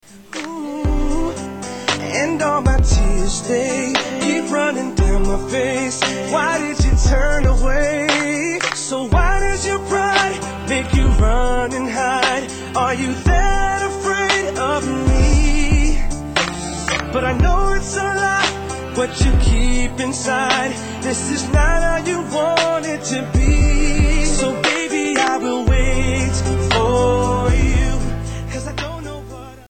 R & B Pop